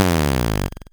fall-b.ogg